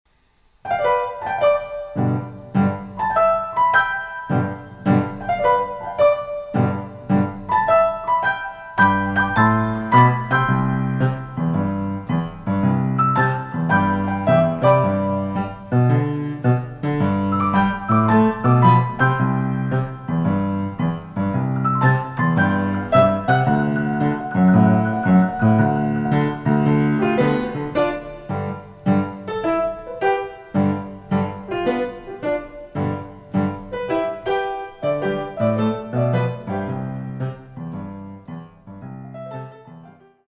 Style: Blues Piano
Einfach gehalten.